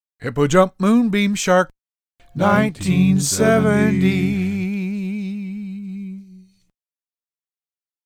jingle to remember it!